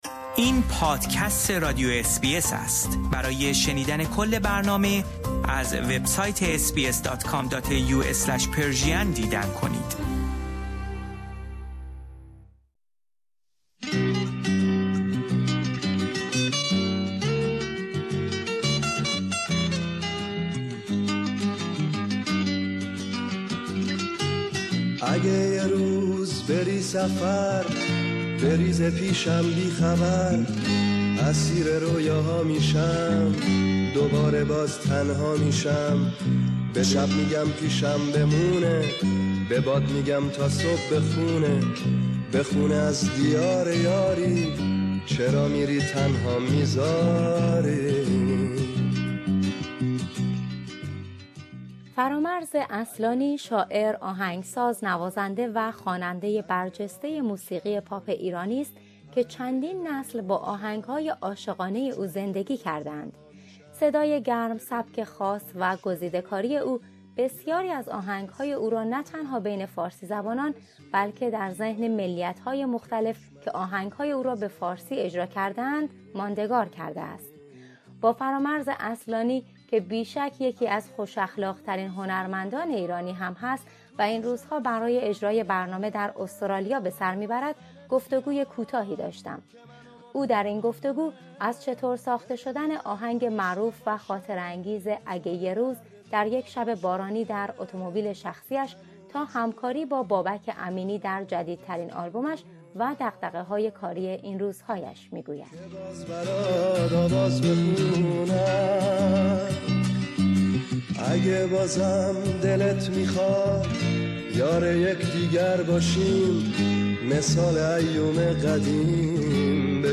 در گفتگو با بخش فارسی رادیو اس بی اس